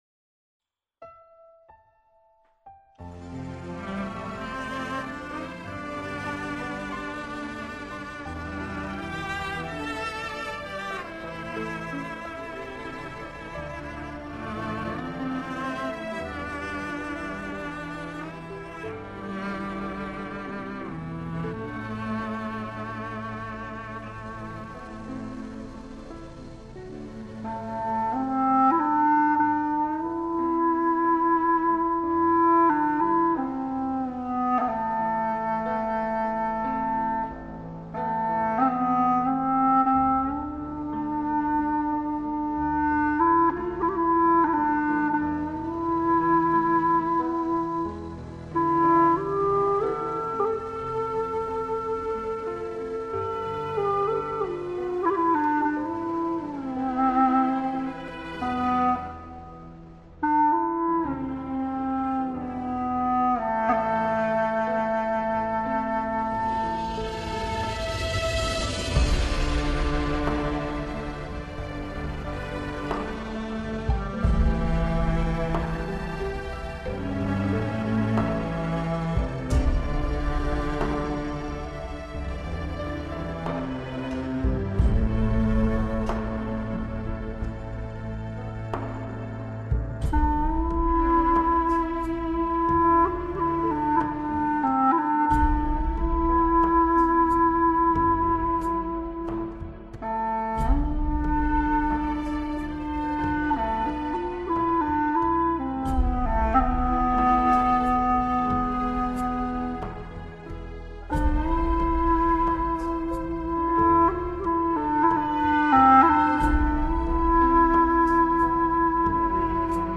调式 : C 曲类 : 独奏 此曲暂无教学 点击下载 又是一年秋风凉，又是一地落叶黄。
忧伤的旋律让我们沉思